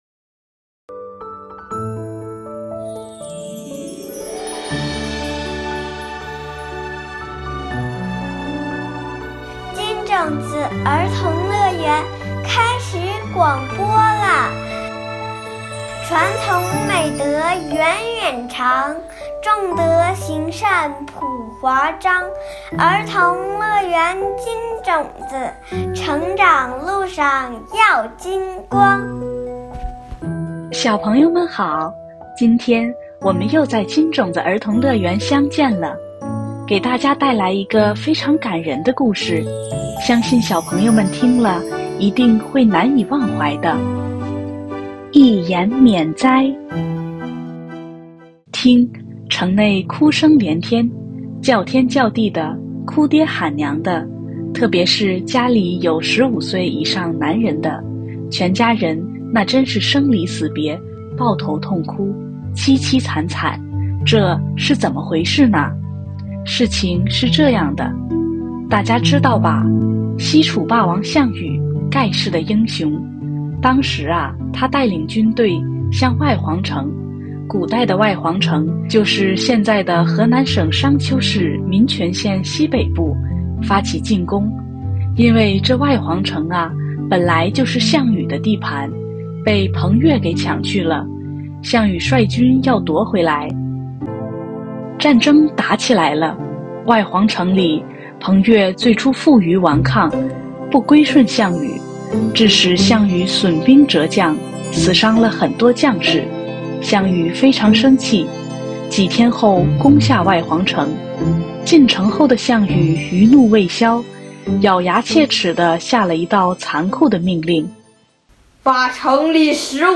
金種子兒童樂園系列廣播故事（音頻）： 第四期《一言免災》